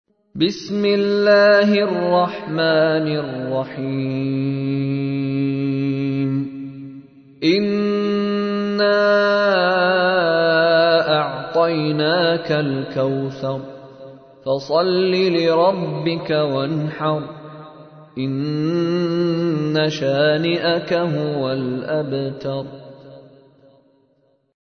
ترتیل سوره کوثر با صدای مشارى راشد ازکویت (98.13 KB)